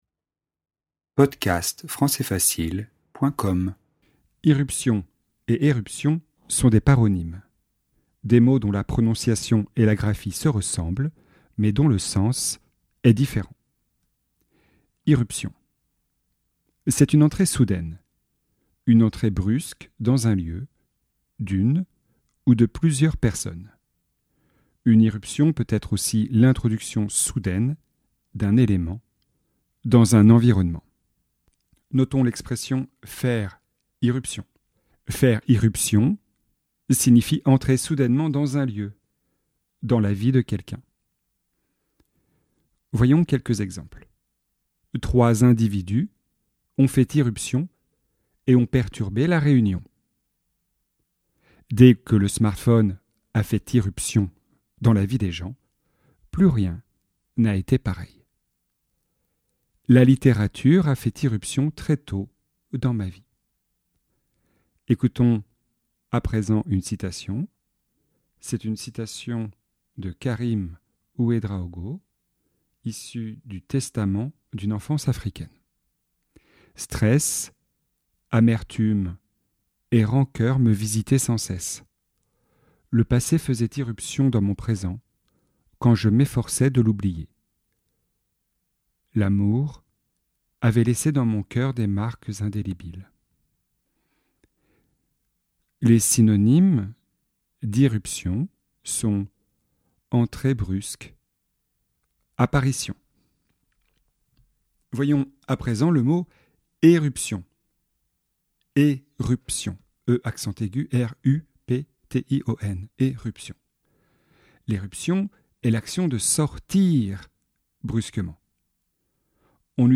Leçon de vocabulaire, niveau avancé (C1) sur le thème des paronymes.